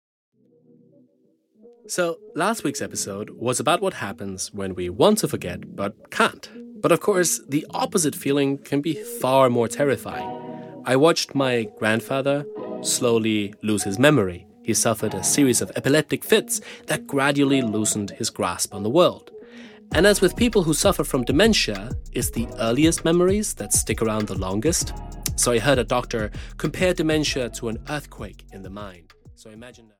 Audio knihaGoing Blank (EN)
Ukázka z knihy